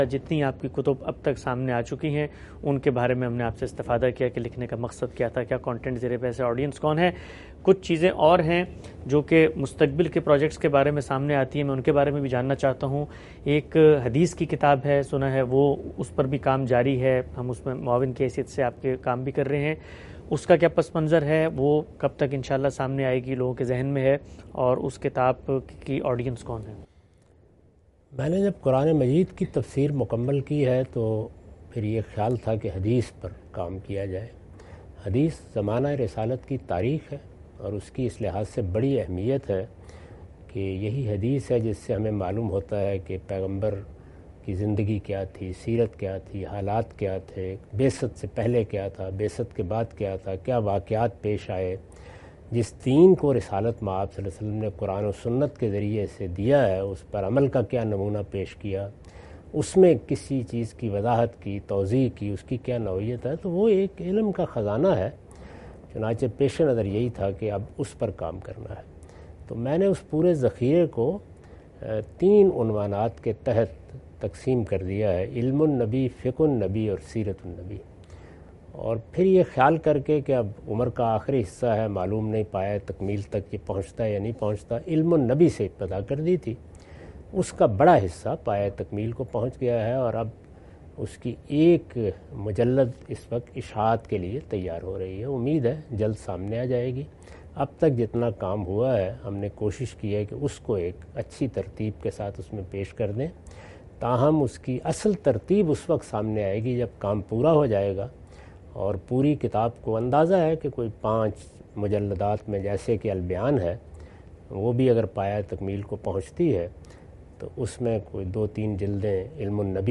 Category: Reflections / Questions_Answers /
In this video, Mr Ghamidi answer the question about "Introduction to the books of Javed Ahmed Ghamidi (Knowledge of the Prophet, Jurisprudence of the Prophet, Sirat-un-Nabi, Research on Hadith)".